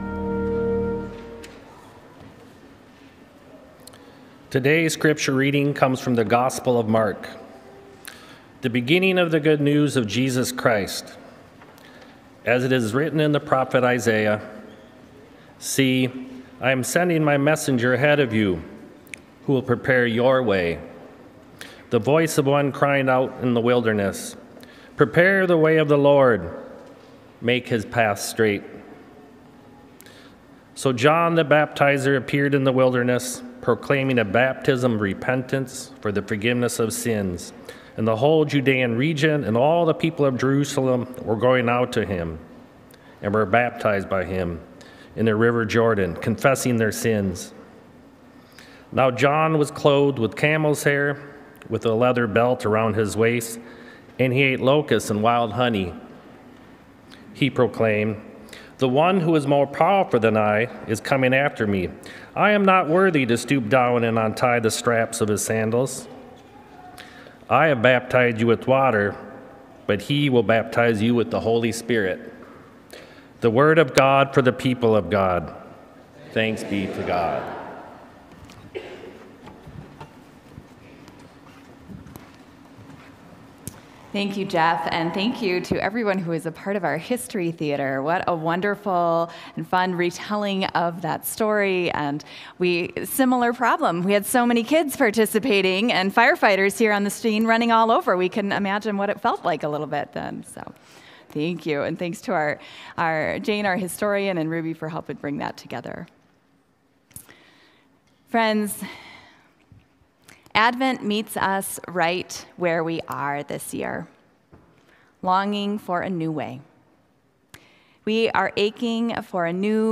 Join us this Sunday for the Second Sunday of Advent as we continue our Sanctuary: Room for All worship series. We will light the Candle of Peace as we explore together how to make room for Jesus in our hearts and minds.